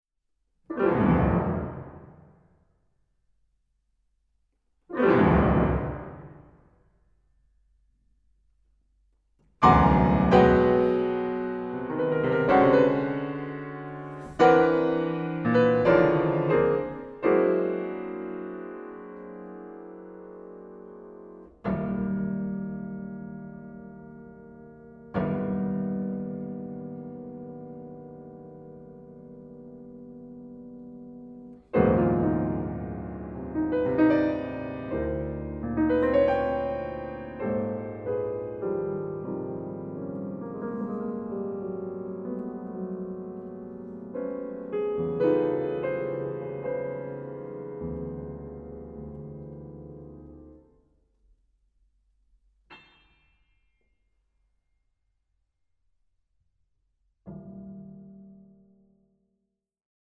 Klavierwerken aus sechs Jahrhunderten